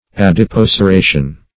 Search Result for " adipoceration" : The Collaborative International Dictionary of English v.0.48: Adipoceration \Ad`i*poc`er*a"tion\, n. The act or process of changing into adipocere.